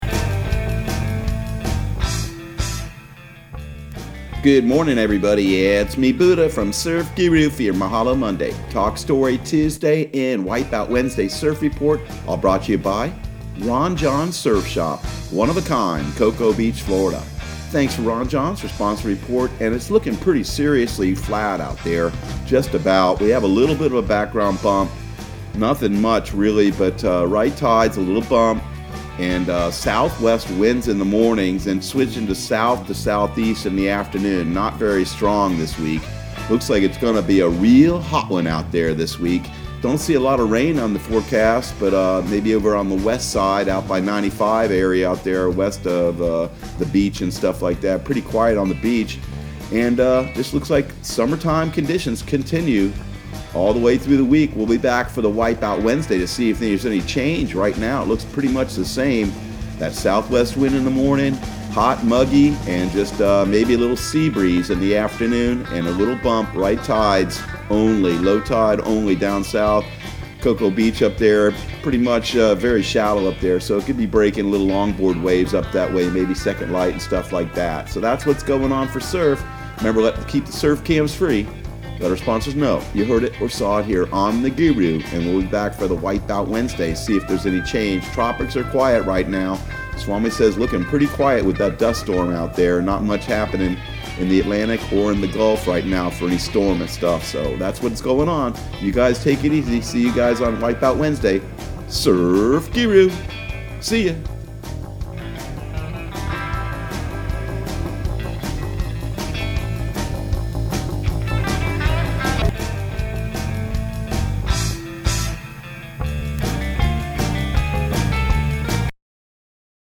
Surf Guru Surf Report and Forecast 06/29/2020 Audio surf report and surf forecast on June 29 for Central Florida and the Southeast.